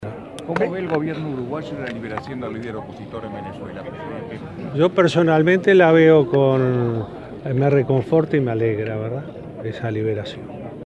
Vázquez fue consultado por la prensa sobre el tema previo al Consejo de Ministros abierto de este lunes en Cerro Largo.